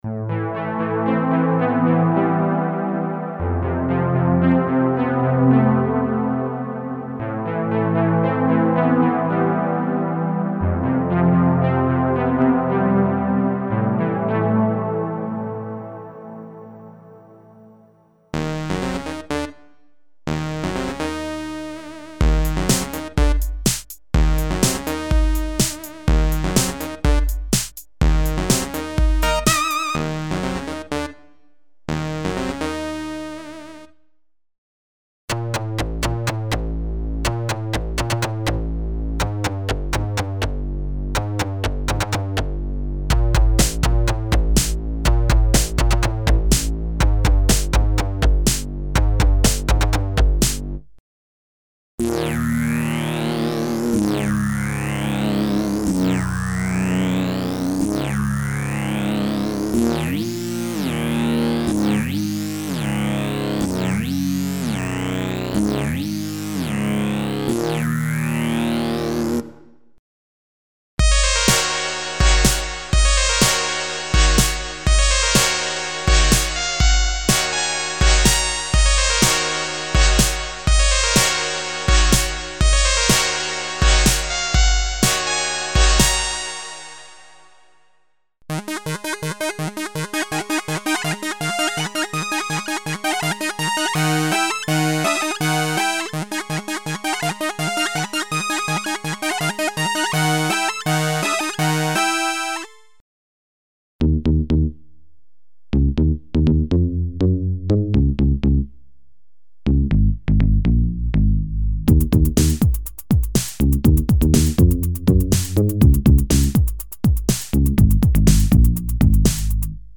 Emulations of vintage digital synthesizers - PPG, DX (FM synthesis) program variations (synth basses, mono leads, poly synths and seq. programs).
Info: All original K:Works sound programs use internal Kurzweil K2500 ROM samples exclusively, there are no external samples used.